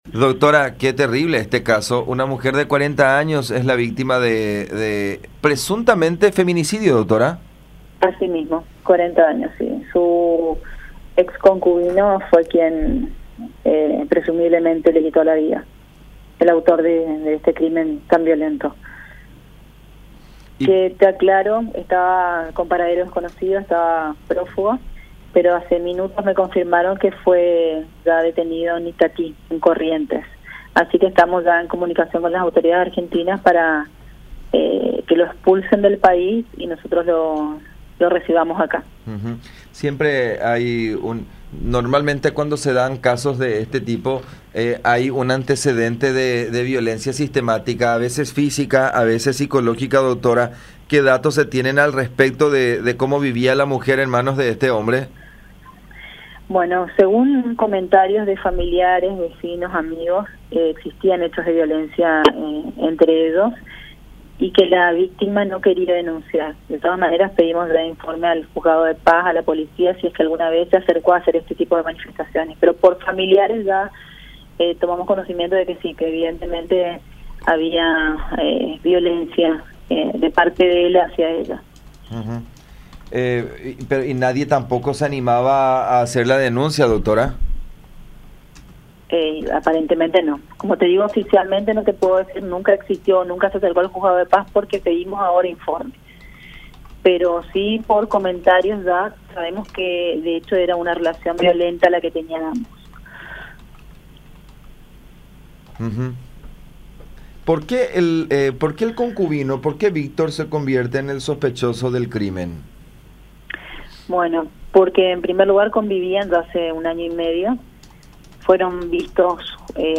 11-FISCAL-CLAUDIA-ALONZO.mp3